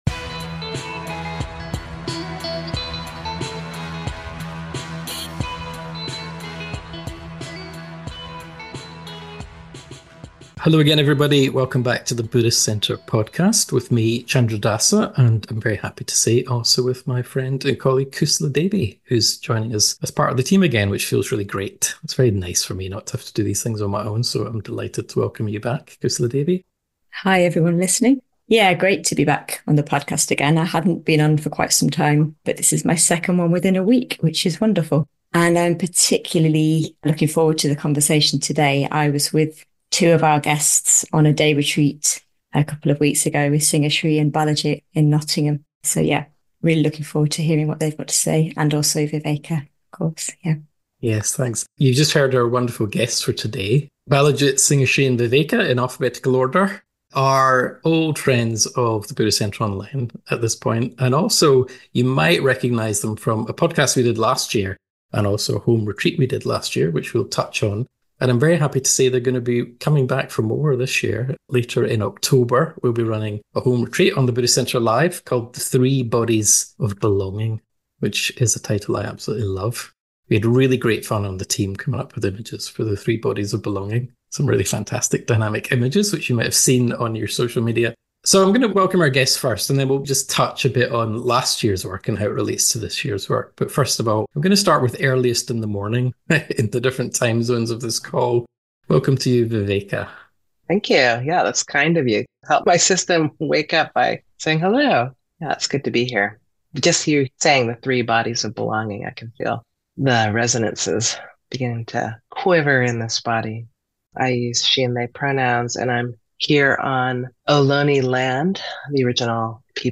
A deeply affirming conversation about healing in body, heart and mind around, through and past trauma and suffering - in ourselves and in the world.